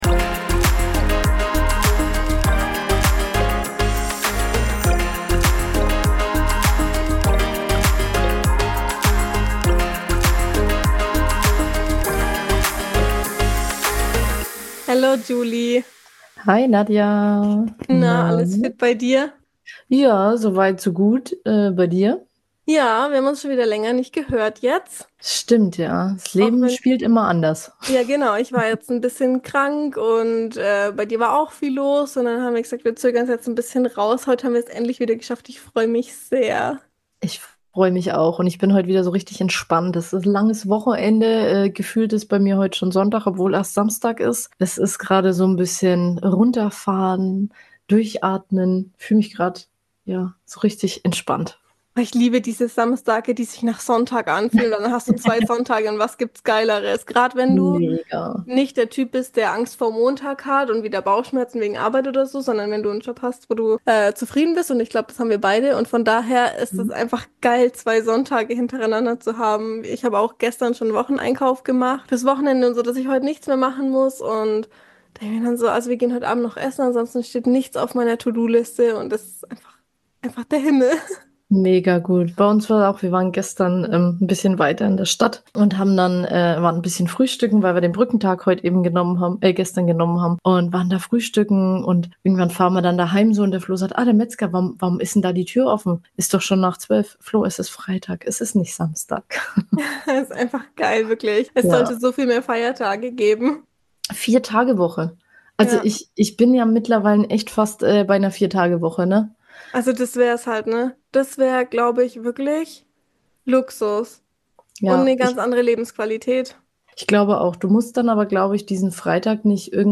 Zwei junge Frauen, mitten im Alltagschaos, nehmen dich mit auf ihre Reise durch die Herausforderungen des Lebens. Zwischen Parkplatzsuche und dem Streben nach den eigenen Big Five sprechen sie über Alltag, Selbstzweifel, Social Media Wahnsinn und all die Dinge, die sich manchmal schwerer anfühlen, als sie sollten.